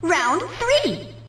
snd_boxing_round3.ogg